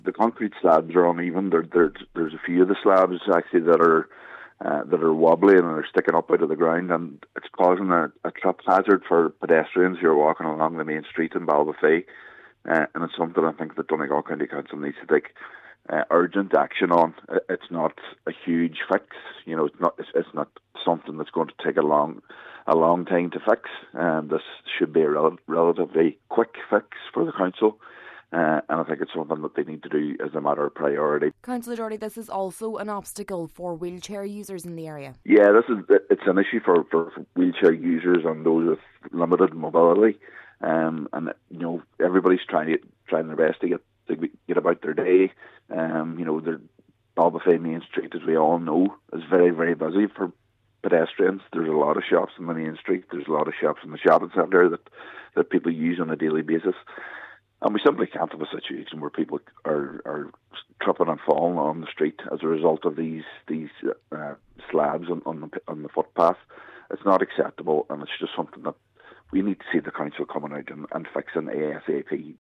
He says that the loose paving poses a particular risk to those with limited mobility: